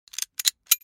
دانلود آهنگ کلیک 25 از افکت صوتی اشیاء
دانلود صدای کلیک 25 از ساعد نیوز با لینک مستقیم و کیفیت بالا
جلوه های صوتی